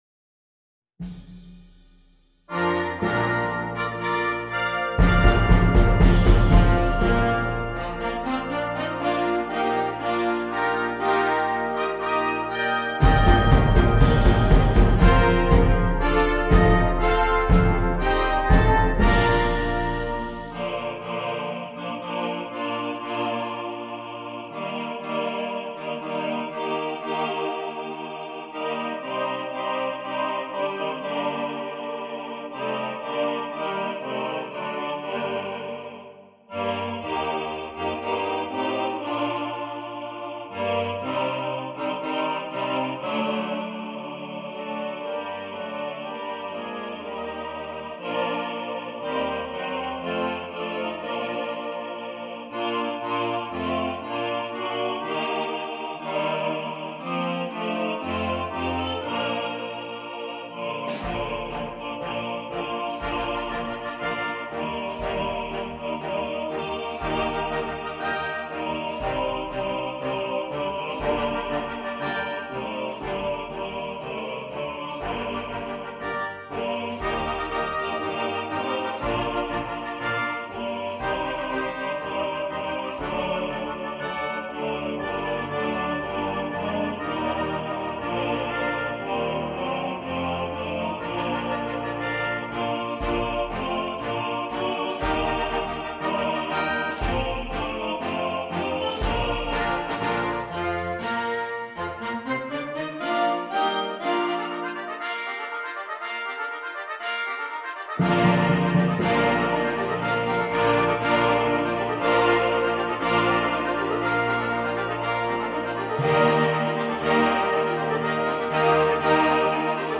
Rätoromanisches Volkslied
für Concert Band und Männerchor
Besetzung: Male Choir & Concert Band